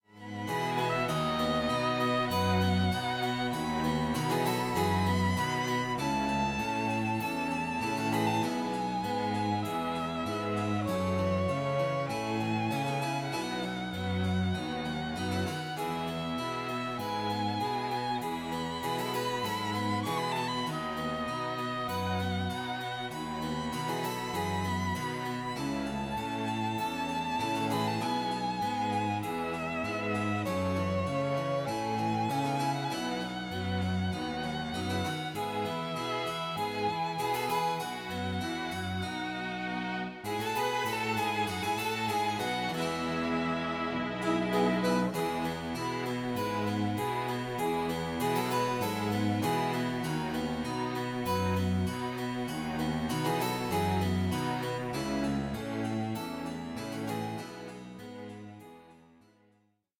Das Playback-Album zur gleichnamigen Produktion.
Playback ohne Backings, gleiche Reihe 2,99 €